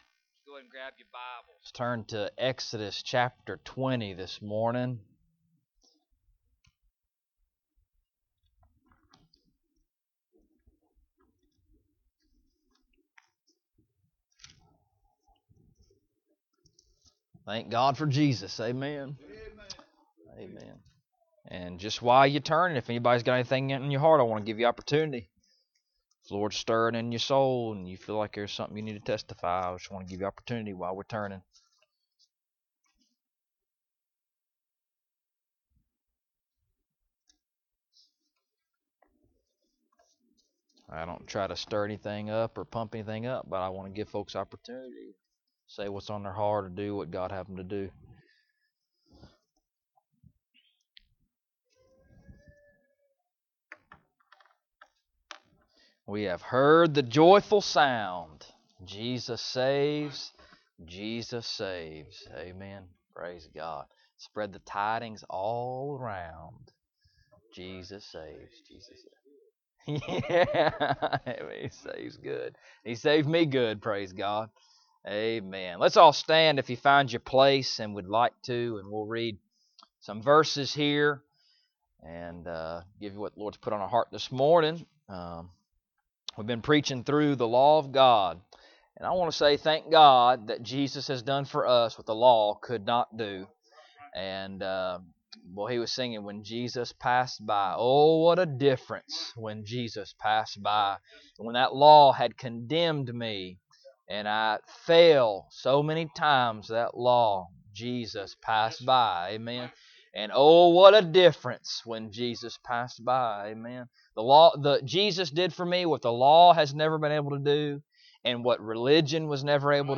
Exodus 20:15 Service Type: Sunday Morning Bible Text